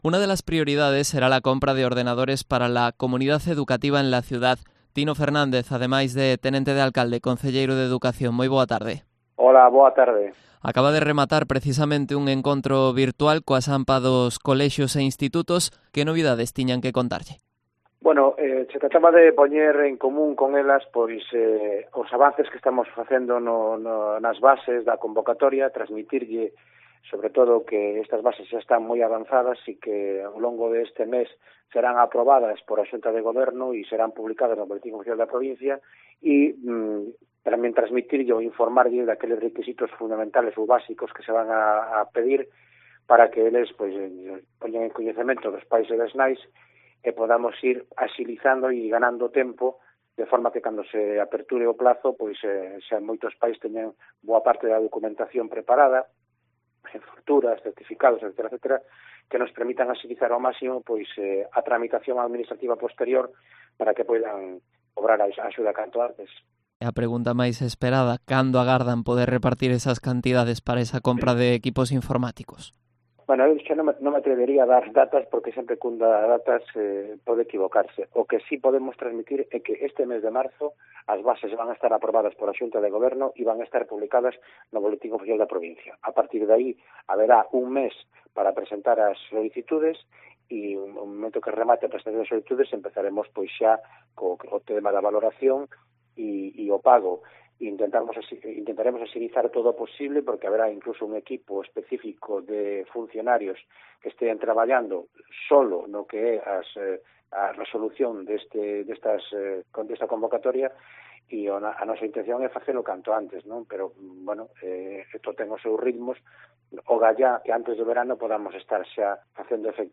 Entrevista a Tino Fernández, concejal de Educación de Pontevedra